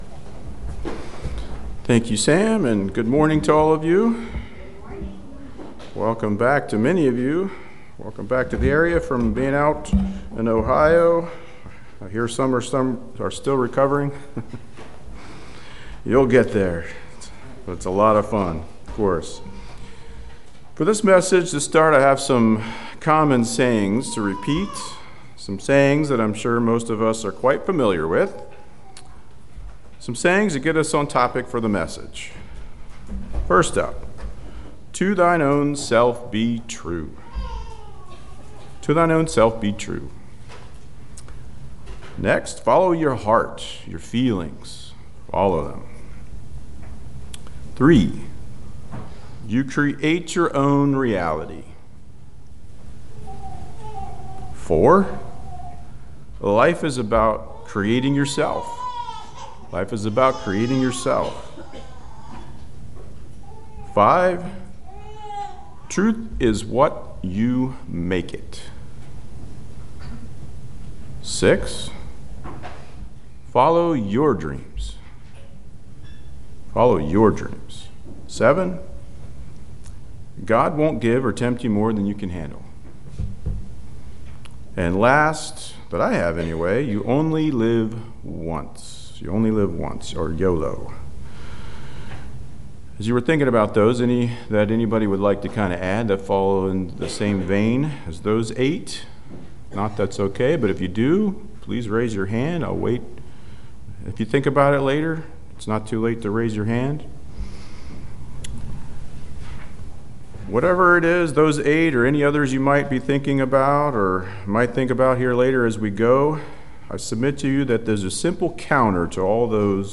Sermons
Given in Lewistown, PA